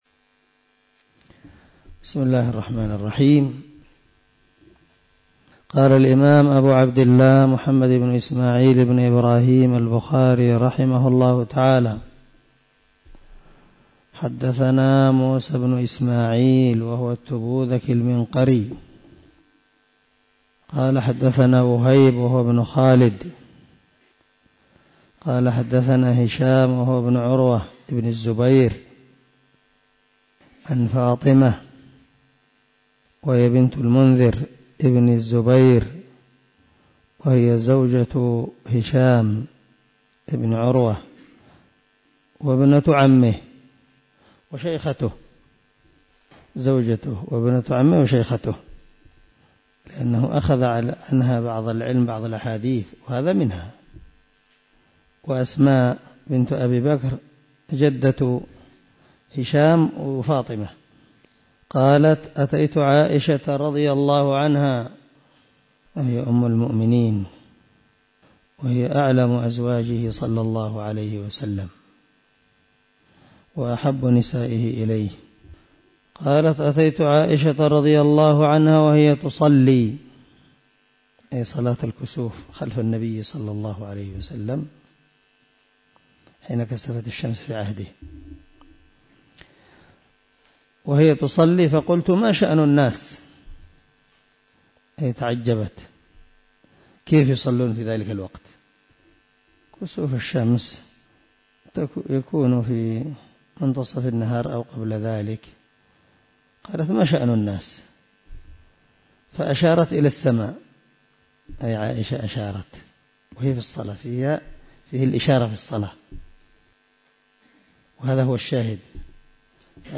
084الدرس 29 من شرح كتاب العلم حديث رقم ( 86 ) من صحيح البخاري